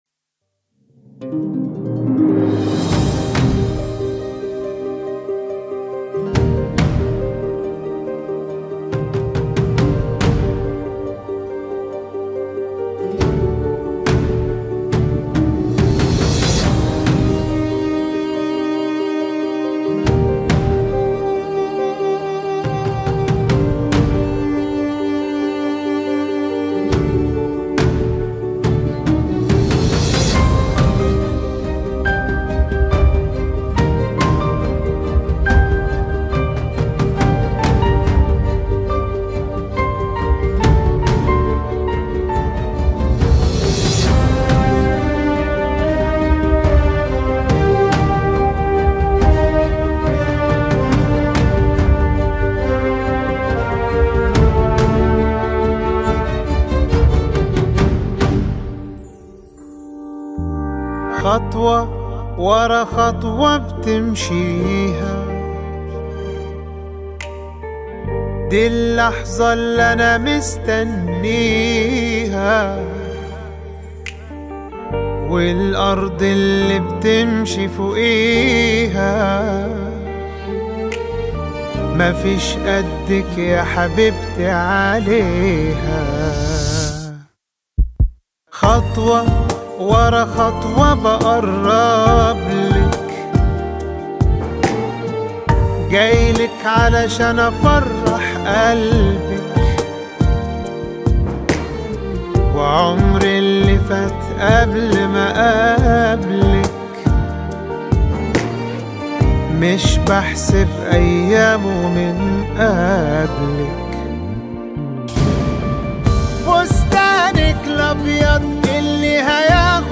زفة رومانسية ملكية تُناسب دخول العروسة